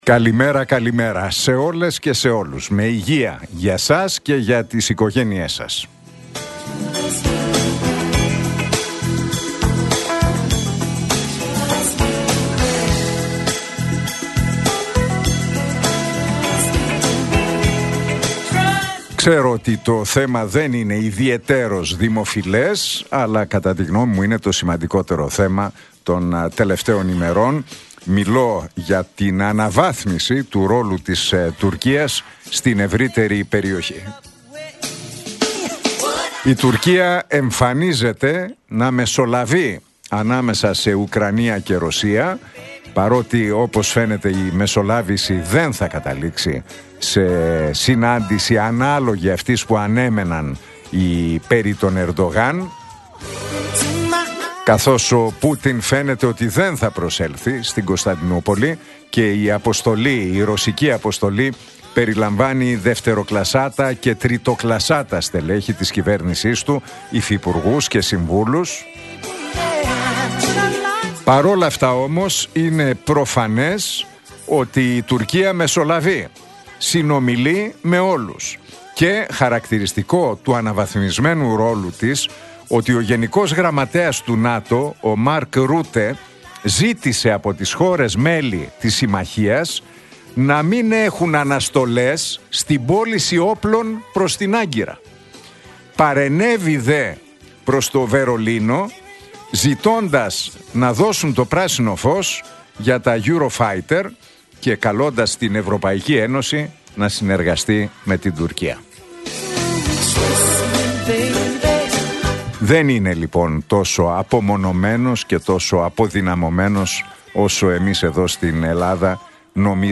Ακούστε το σχόλιο του Νίκου Χατζηνικολάου στον ραδιοφωνικό σταθμό Realfm 97,8, την Πέμπτη 15 Μαΐου 2025.